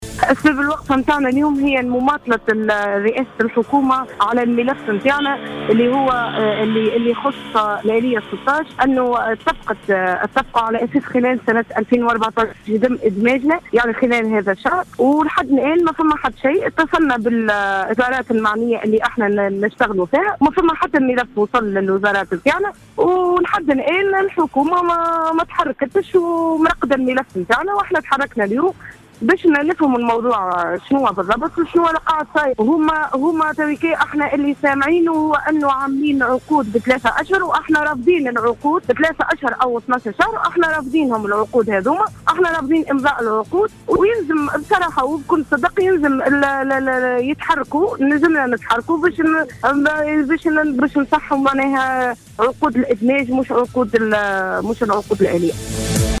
وأكدت إحدى المحتجات في تصريح ل"جوهرة أف أم" أن التحرّك يأتي على خلفية ما اعتبروه "مماطلة" من قبل السلط المعنية في تسوية وضعياتهم المهنية و للمطالبة بالإدماج.